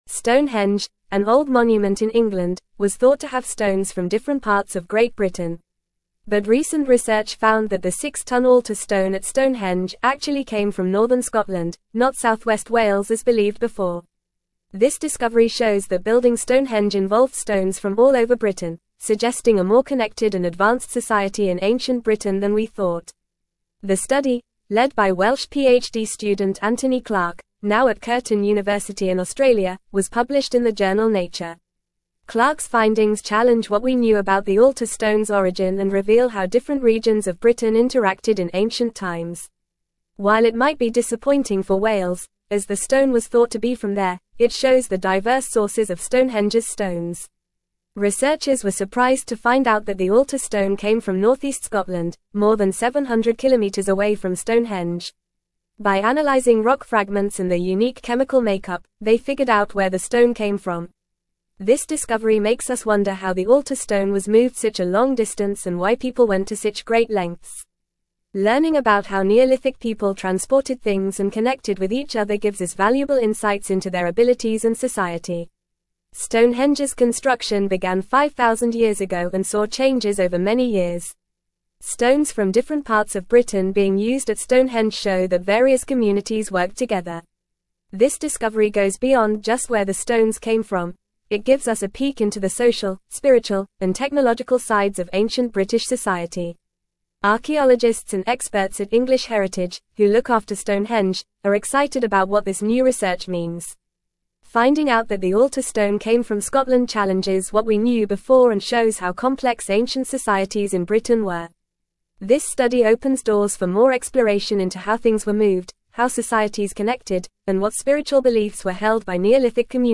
Fast
English-Newsroom-Upper-Intermediate-FAST-Reading-Stonehenge-Altar-Stone-Originates-from-Scotland-Not-Wales.mp3